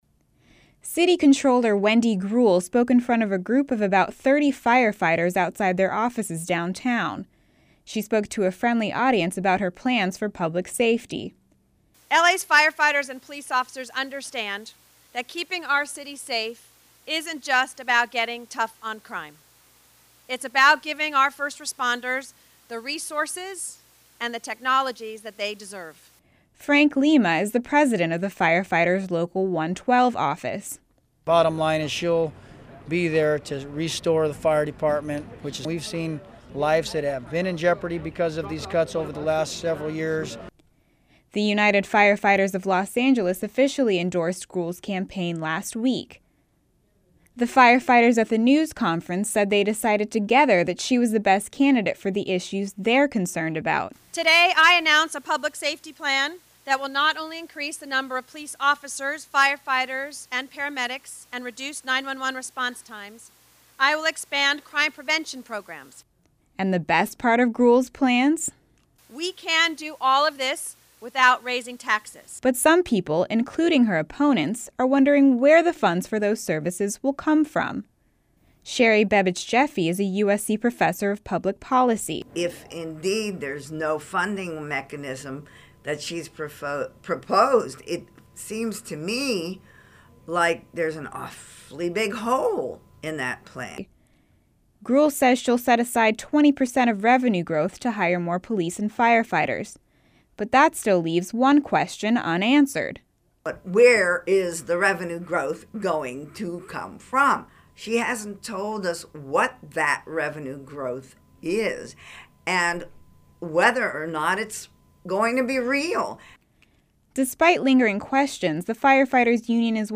Mayoral Candidate and current City Controller, Wendy Greuel, spoke in front of a friendly audience of about 30 firefighters outside their offices downtown about her plans for public safety.